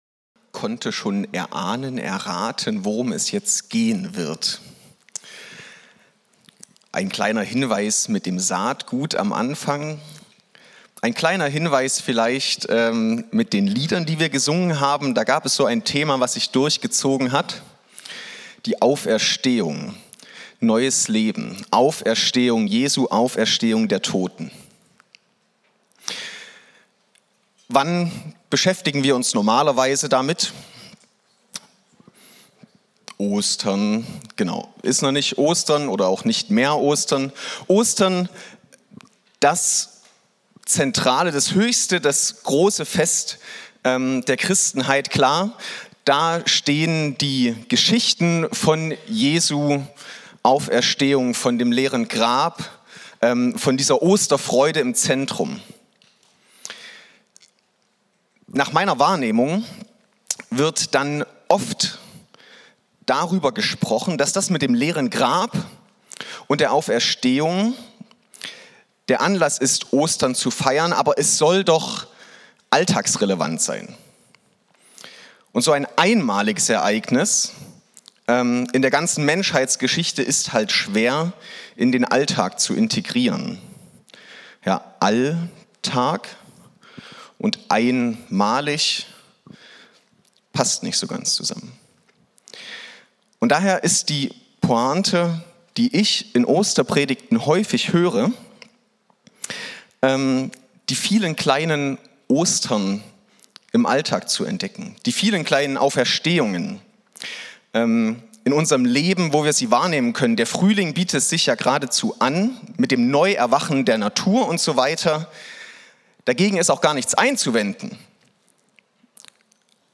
Alle Predigten des Kirchenjahres der Kreuzkirche Tübingen zum Download.
Musikpredigt mit 4 Impulsen - die Gitarre als Gleichnis